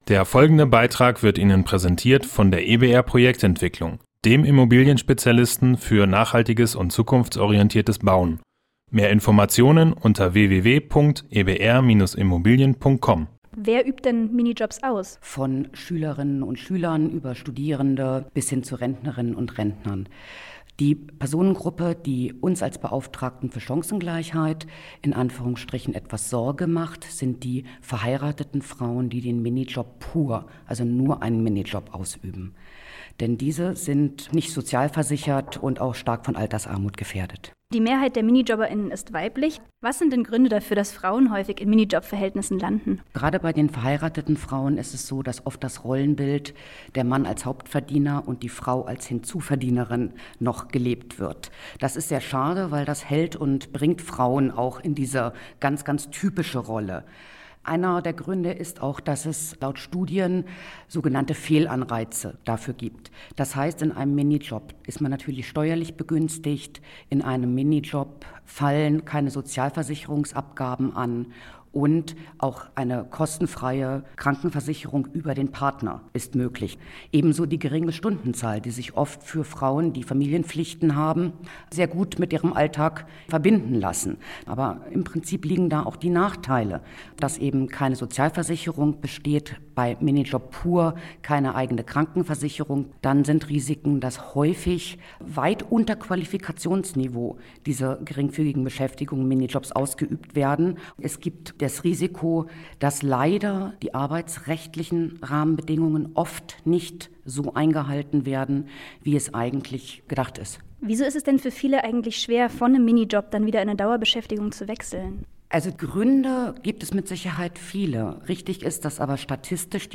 Beiträge > Was Beschäftigte über Minijobs wissen sollten - StadtRadio Göttingen